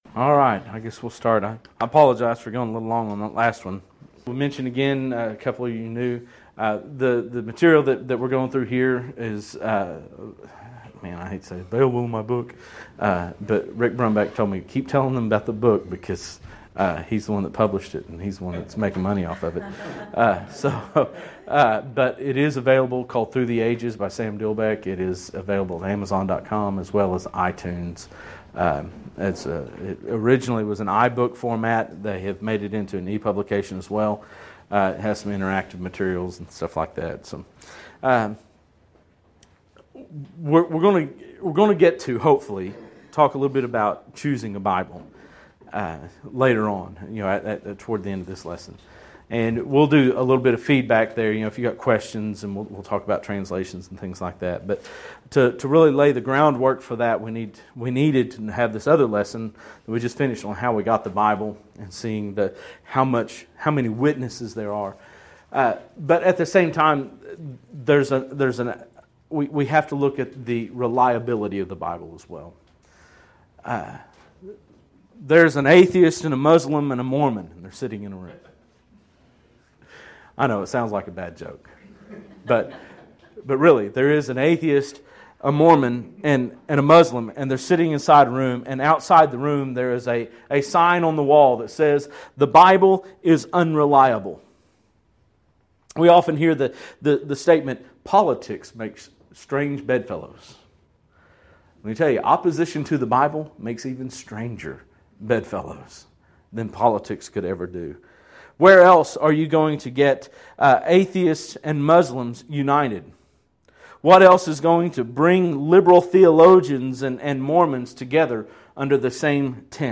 Event: 2014 Discipleship U
lecture